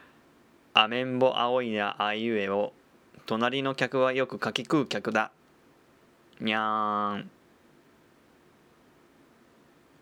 オリジナル音声はYAMAHAのAG03とSonyのECM-PCV80Uです(なんかいろいろ間違えてますが気にしないでください)